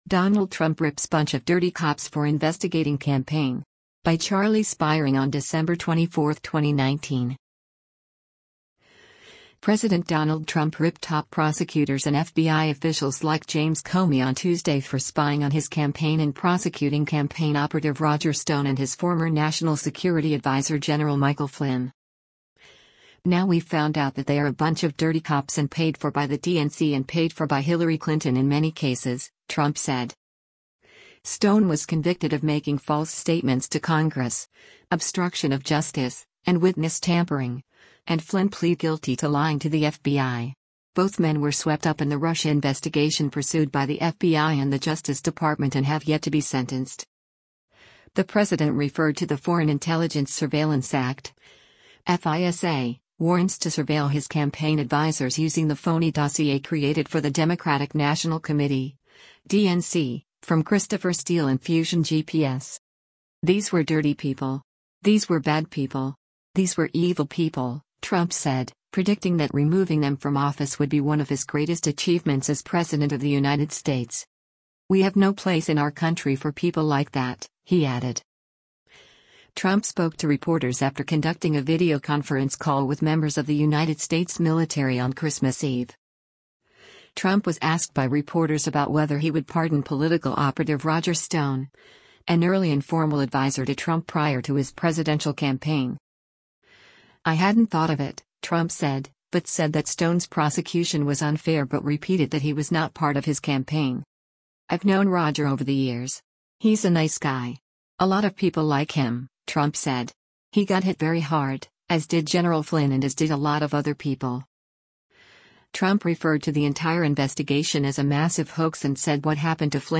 US President Donald Trump answers questions from reporters after making a video call to th
Trump spoke to reporters after conducting a videoconference call with members of the United States military on Christmas Eve.